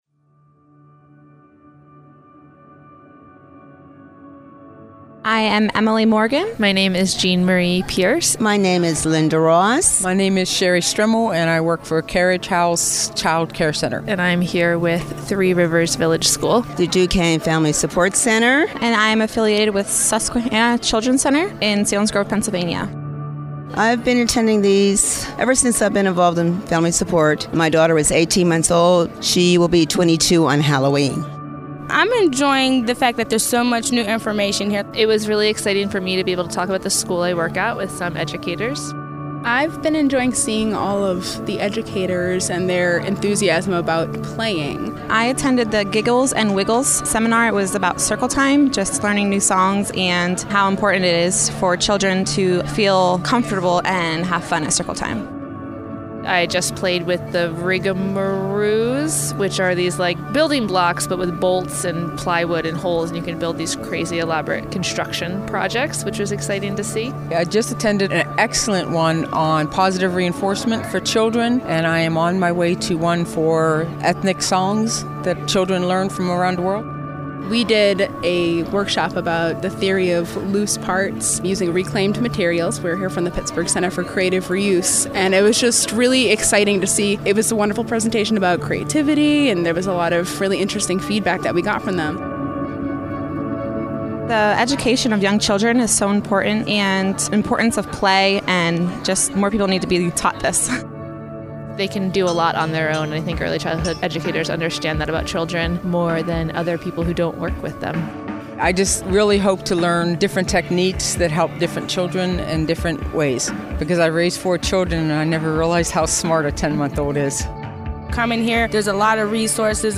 Listen below to hear conference attendees weigh in on the conference itself, and kids and adults alike on the importance of Play!